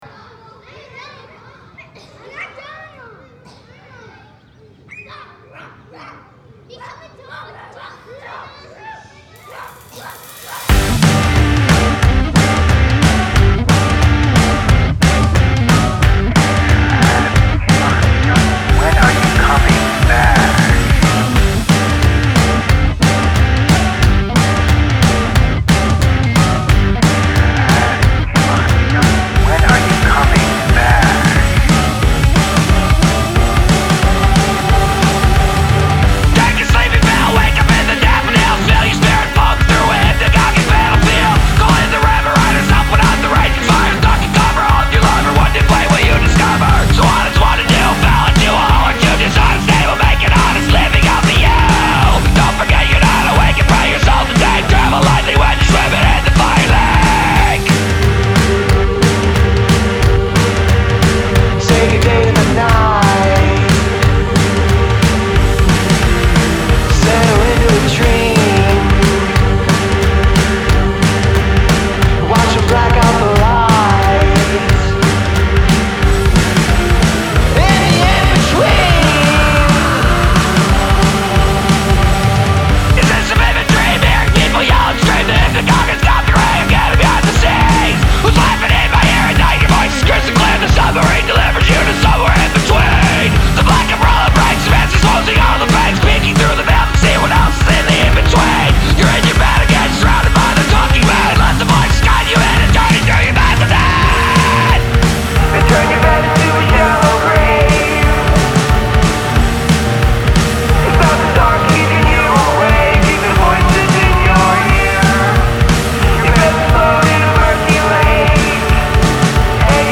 Goggin Lullaby a goggin remembers only those who hum true 🪈 Play Lullaby 🎤 Hum Back ⏹ Silence The goggin listens.